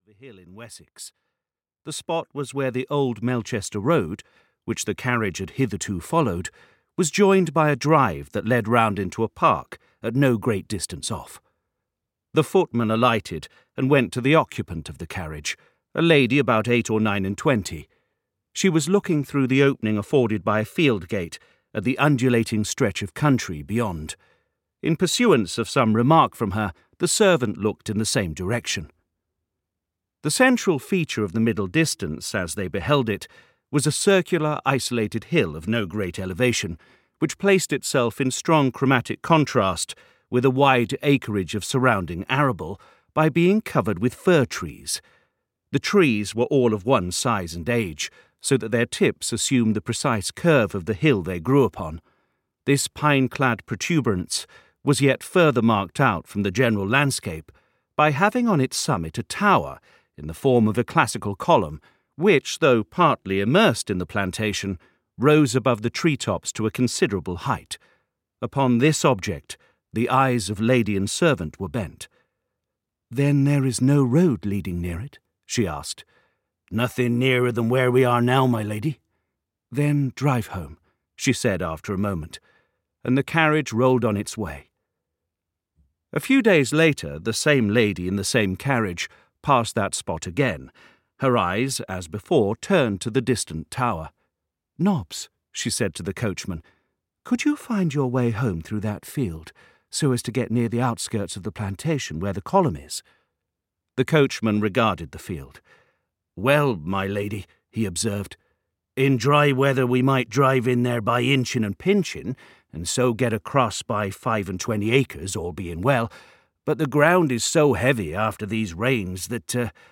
Two on a Tower (EN) audiokniha
Ukázka z knihy